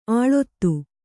♪ āḷottu